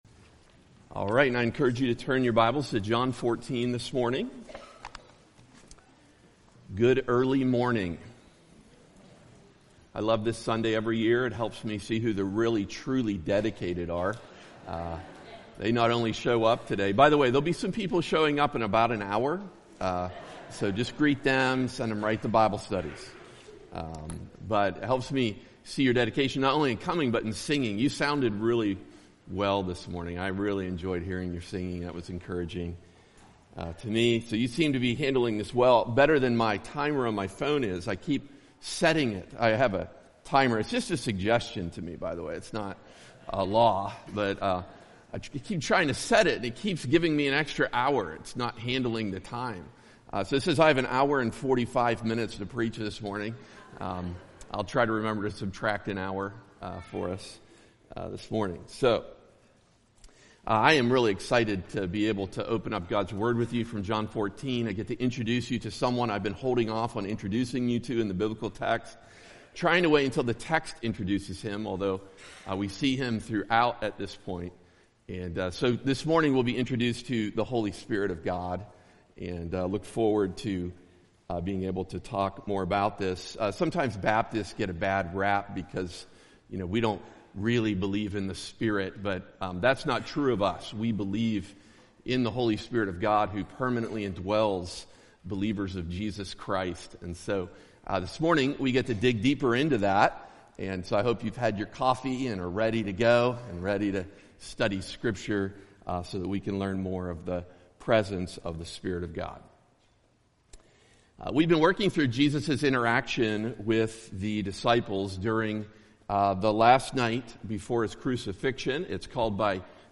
preaches on John 14:15-21